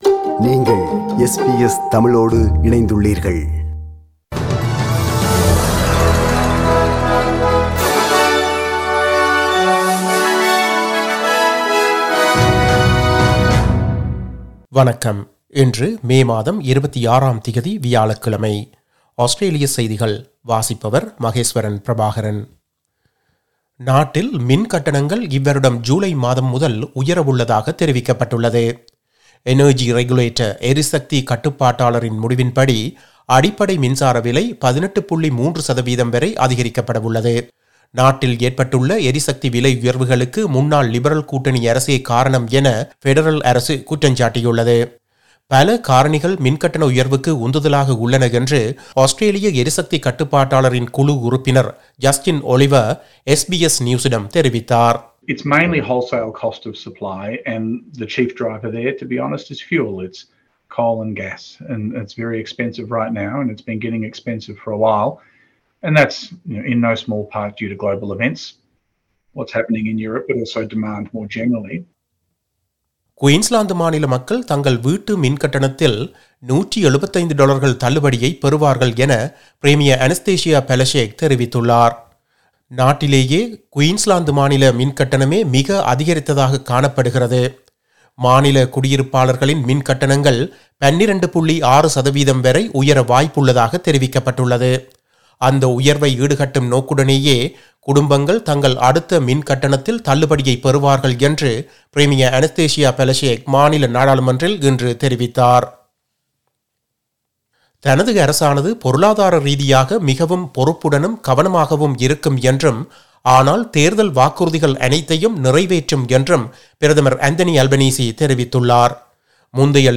Australian news bulletin for Thursday 26 May 2022.